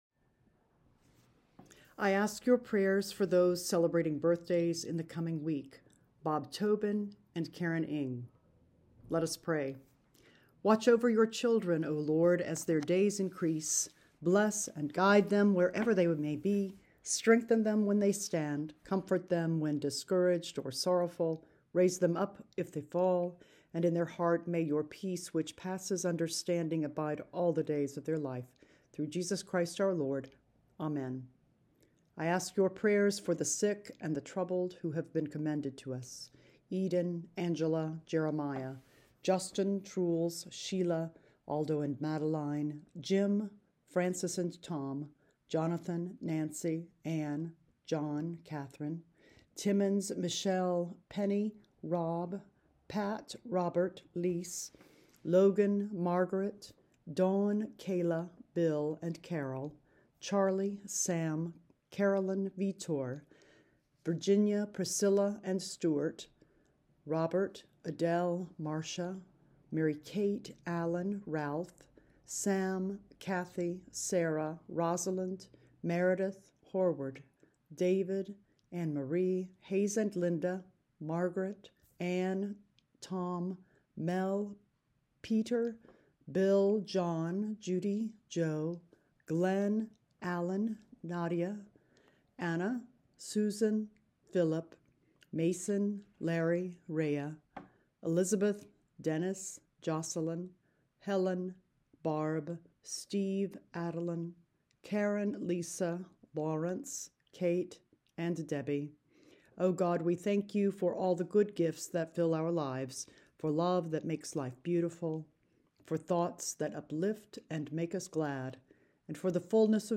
Recording of Weekly Prayers: